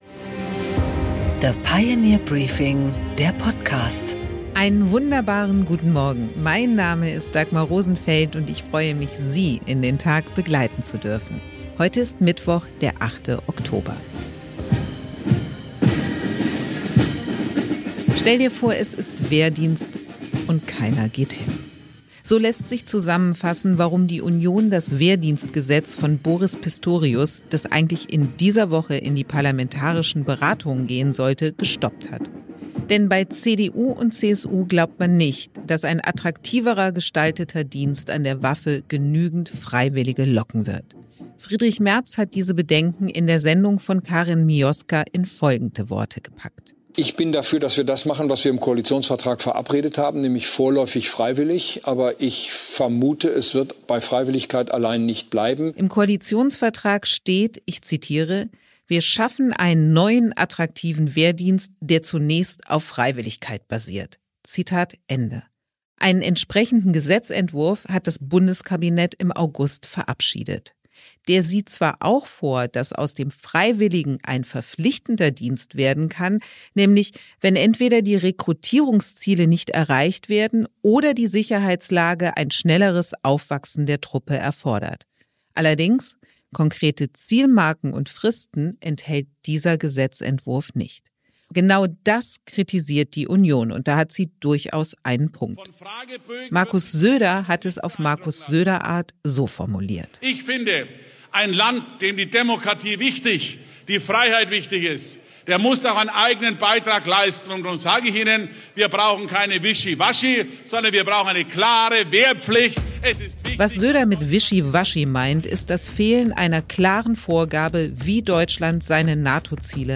Dagmar Rosenfeld präsentiert das Pioneer Briefing
Im Gespräch: Prof. Veronika Grimm, Ökonomin, erläutert im Gespräch mit Dagmar Rosenfeld das neue Gutachten für das Wirtschaftsministerium, das für Aufsehen sorgt.